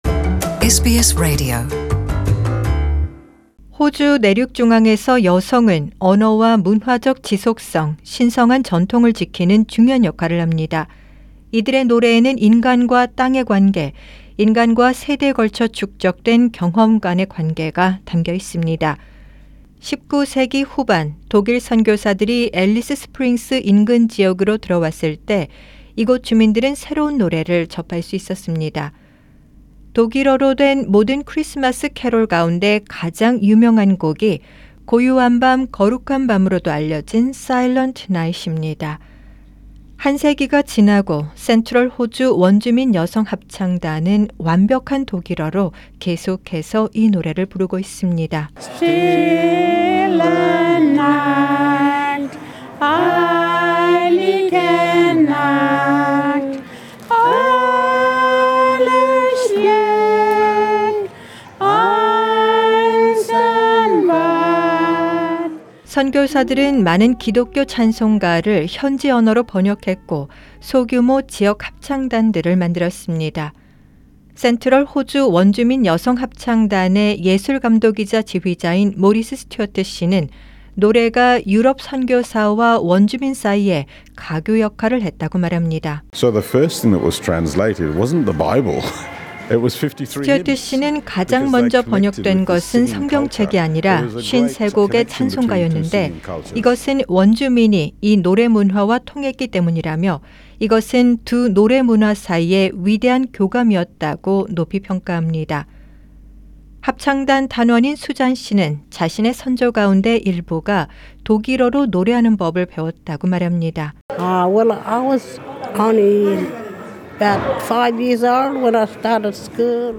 A century later the Central Australian Aboriginal Women’s Choir continues to sing it in perfect German.